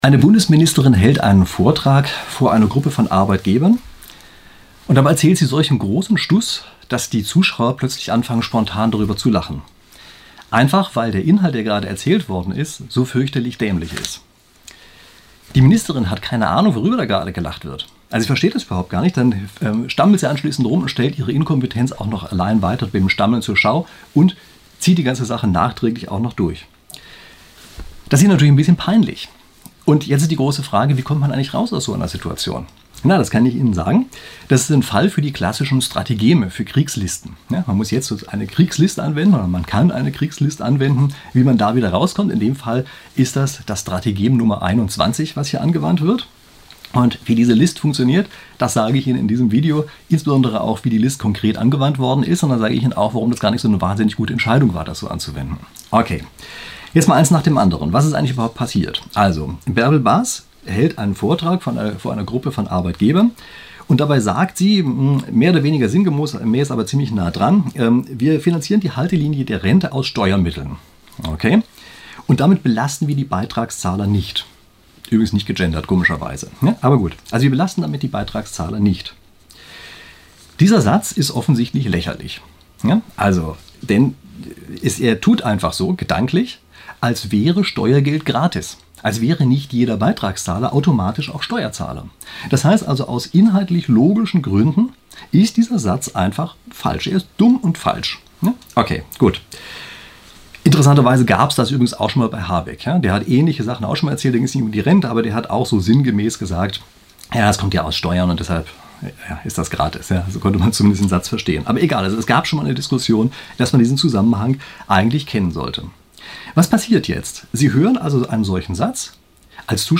Das Publikum lacht, Frau Bas verweigert die inhaltliche Diskussion…
Baerbel_Bas_Lachen_Arbeitgeber(1).mp3